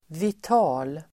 Uttal: [vit'a:l]